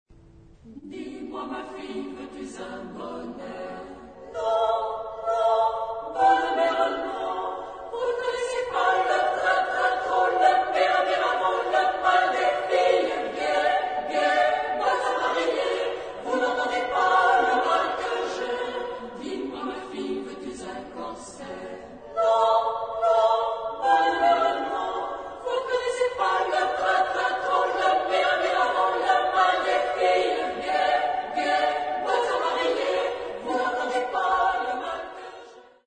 Género/Estilo/Forma: Profano ; Popular
Carácter de la pieza : humorístico
Tipo de formación coral: SSAA  (4 voces Coro femenino )
Tonalidad : si menor